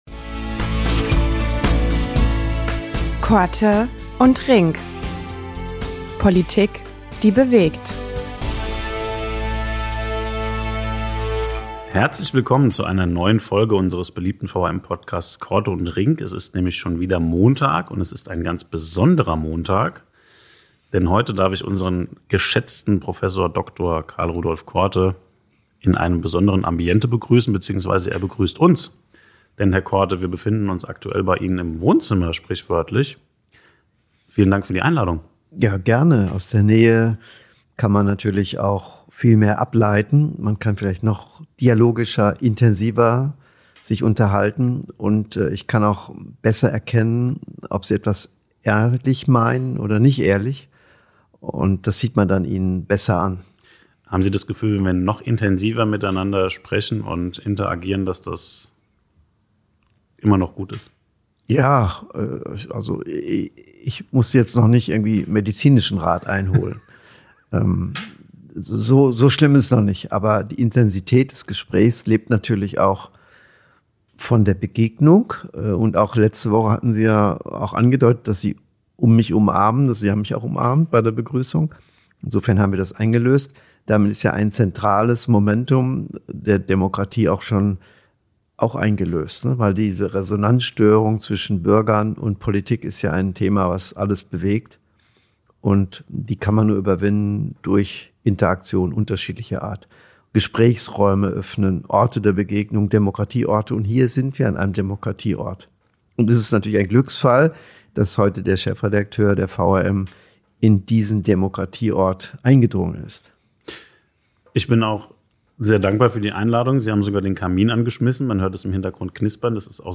Im Schein des Kaminfeuers, bei Plätzchen und Mozartkugeln, diskutieren die beiden über die Funktionsweise politischer Beratung. Wie laufen Beratungsgespräche in der Politik eigentlich ab?
Was macht die Politik am Ende daraus? Ein Gespräch über Nähe, Einfluss und politische Wirklichkeit.